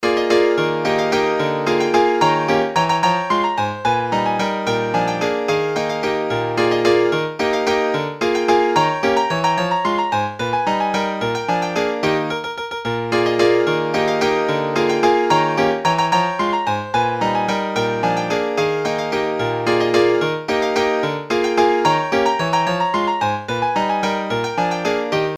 着信音にしやすいようにループサウンドに調整してあります。
クラシック